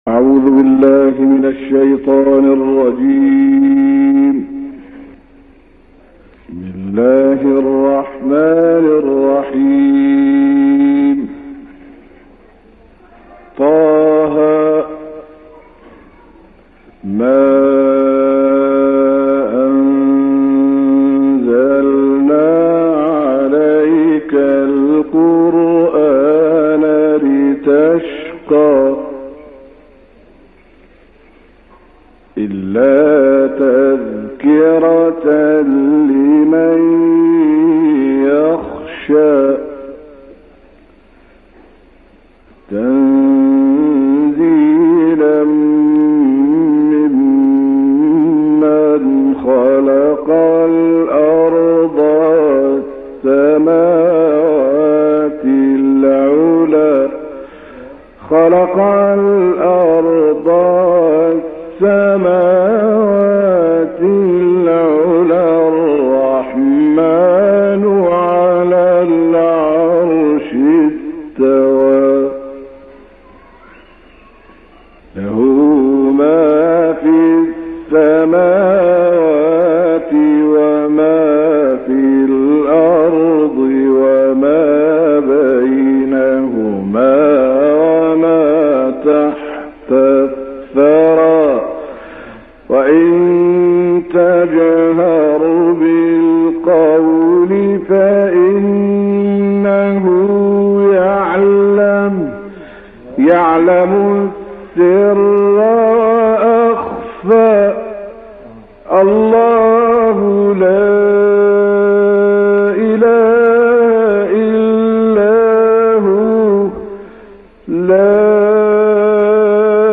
روش ادایی و سبک وی با دیگر قاریان مصری متفاوت بود و این تفاوت با دیگر قاریان نیز به این شکل بود که تلاوت خود را به صورت گفتارگونه انجام می‌داد و سبک خواندن او سبکی گفتاری بود که این روش بسیار مورد توجه شهروندان مصری بود.
تلاوت وی حالتی میان ترتیل و تحقیق است که نه می‌توان گفت ترتیل و نه تحقیق.
البته بین مقام رست و مقام چهارگاه در رفت‌وآمد است.